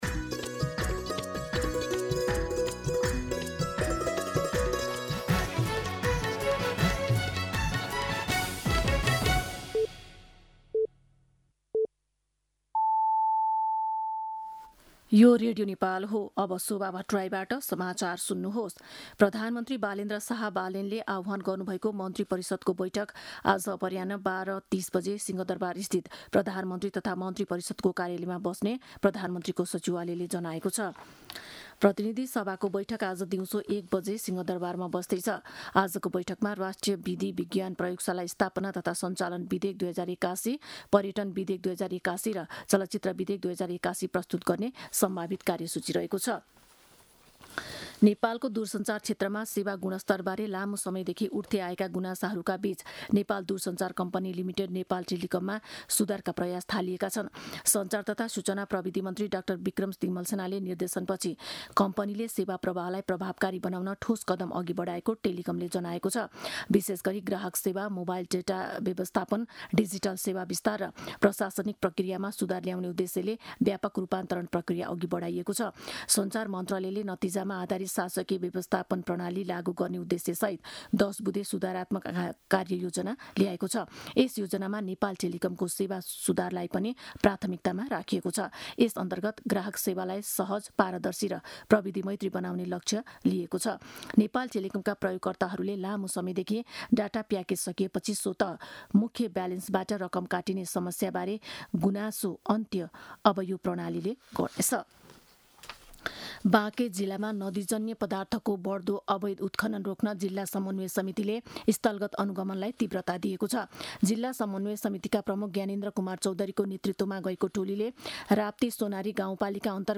मध्यान्ह १२ बजेको नेपाली समाचार : २४ चैत , २०८२